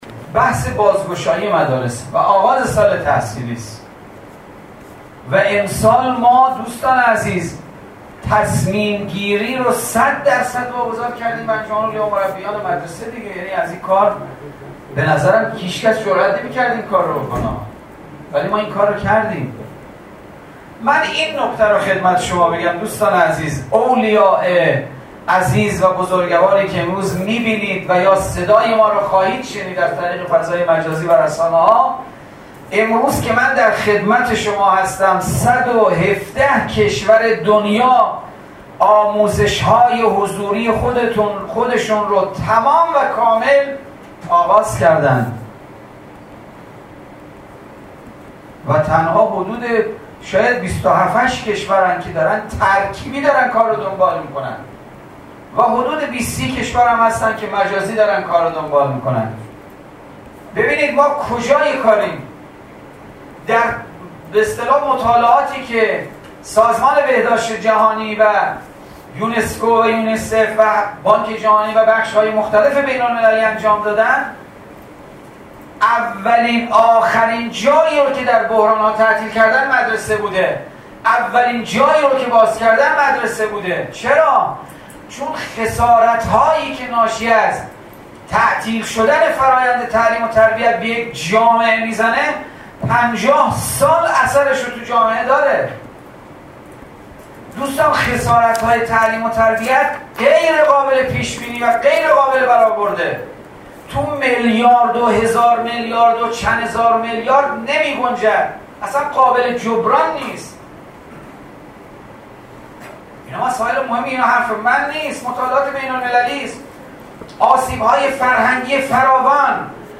به گزارش خبرنگار ایکنا، علیرضا کاظمی، سرپرست وزارت آموزش و پرورش امروز 27 مهرماه در گردهمایی انجمن‌های اولیا و مربیان برتر سراسر کشور که به صورت آنلاین برگزار شد، به تشریح دلایل بازگشایی مدارس در کشور پرداخت و گفت: امسال تصمیم‌گیری بازگشایی مدارس را به صورت 100 درصد به انجمن اولیا و مربیان واگذار کرده‌ایم.